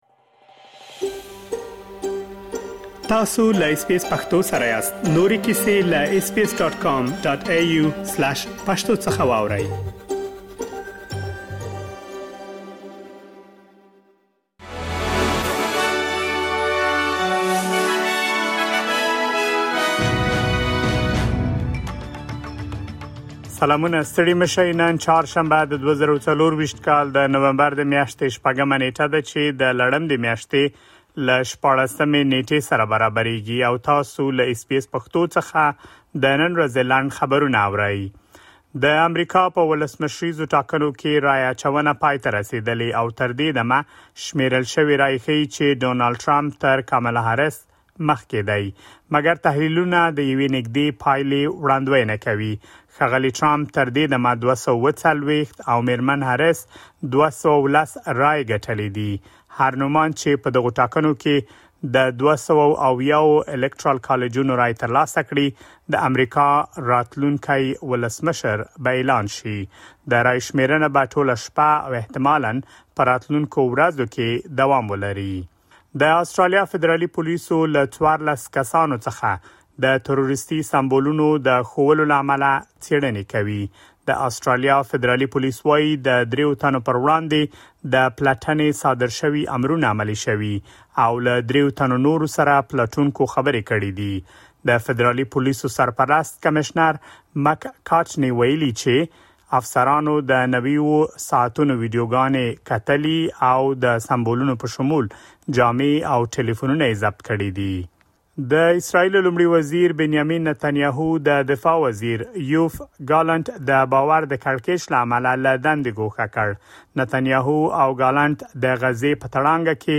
د اس بي اس پښتو د نن ورځې لنډ خبرونه |۶ نومبر ۲۰۲۴